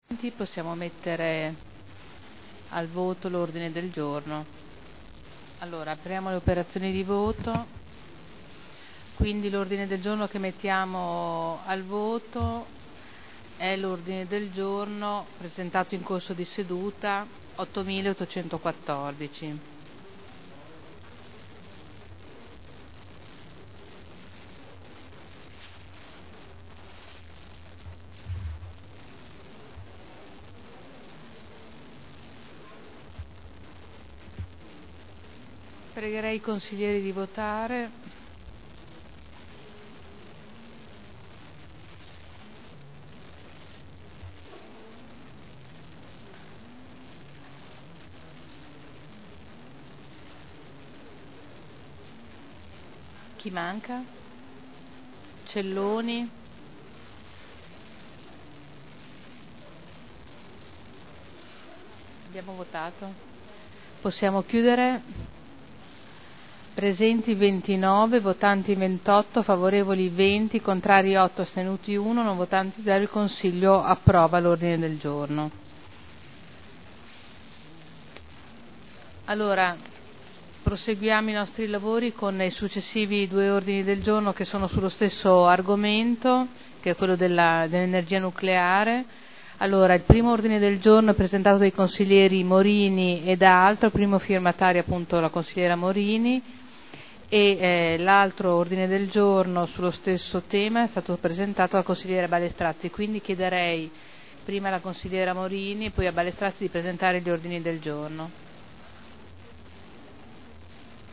Seduta del 24/01/11. Mette ai voti ordine del Giorno presentato in corso di seduta.